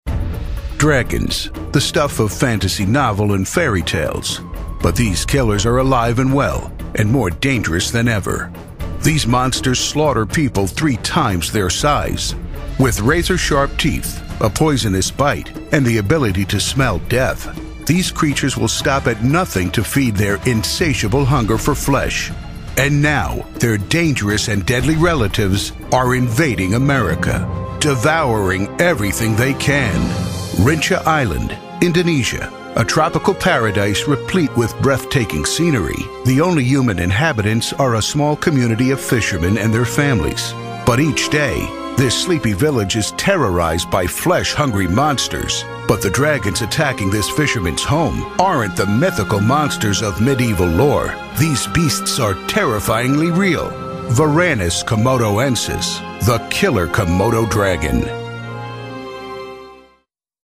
Deep, Epic, Motivational.
Documentary